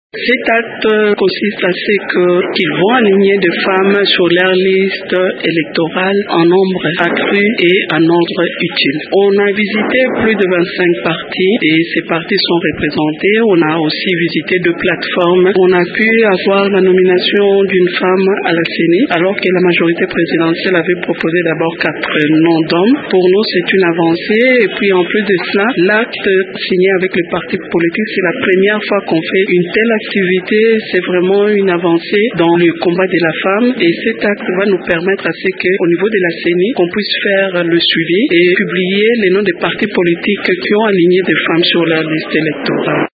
La cérémonie a eu lieu au Centre pour handicapés physiques à Kinshasa.